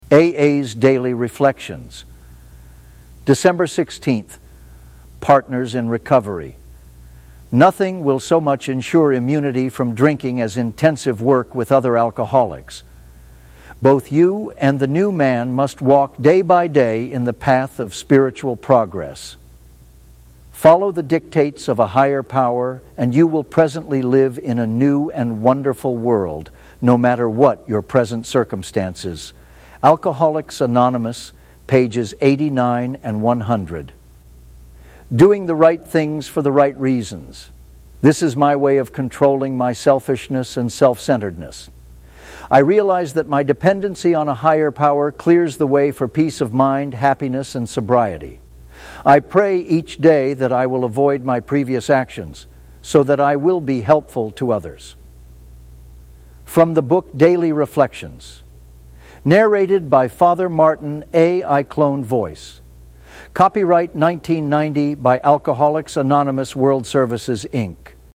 Cloned Voice.